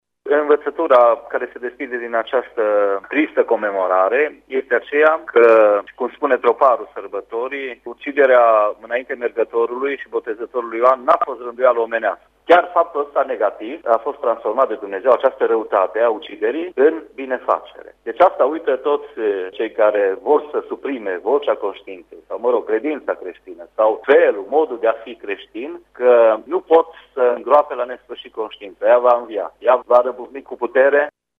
Preotul ortodox din Tg.